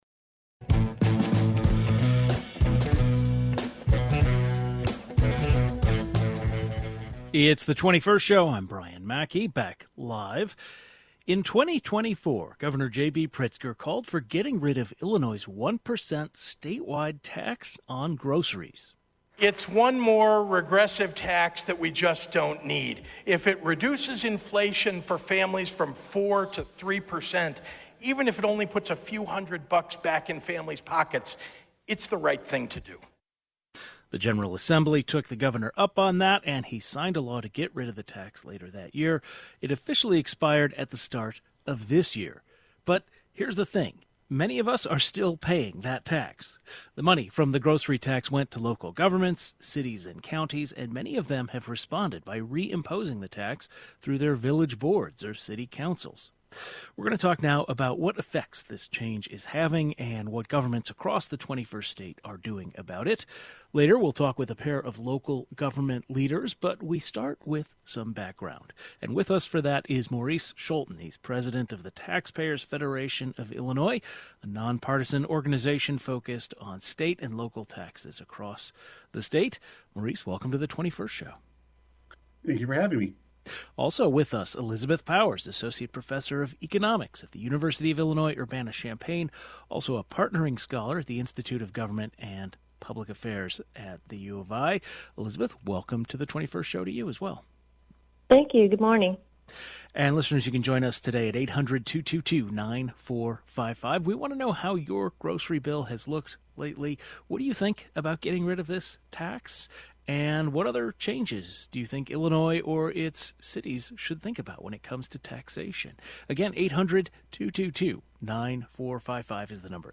Some Illinois mayors and economic experts give their take.